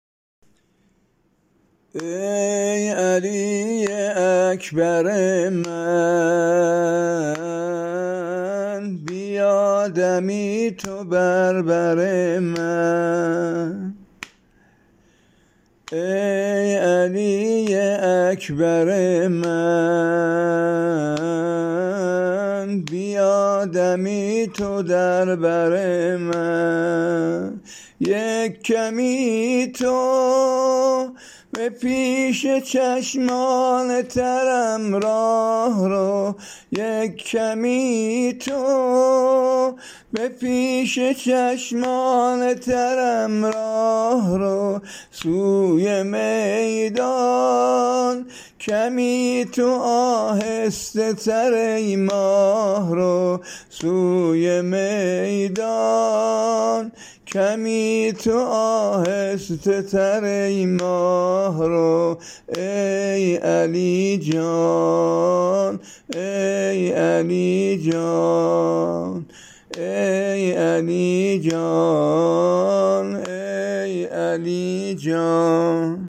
نوحه شهادت علی اکبر